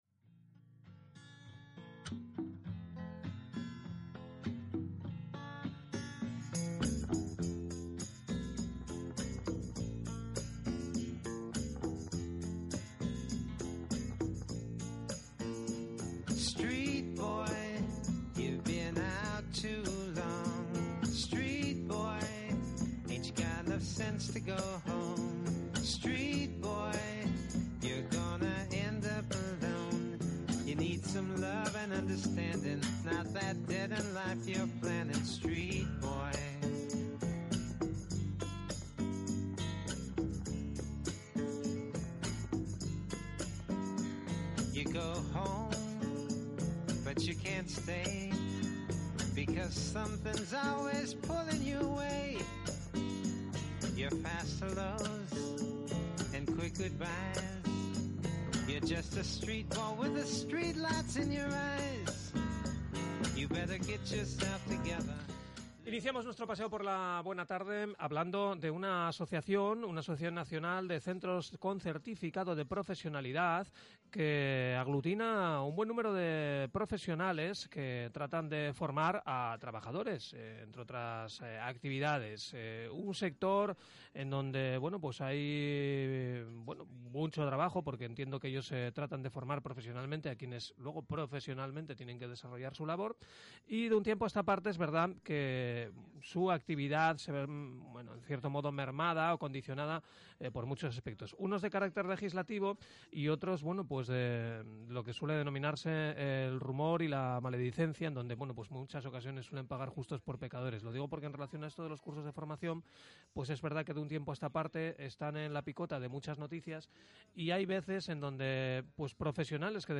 Entrevista RTPA – Formación regulada para trabajadores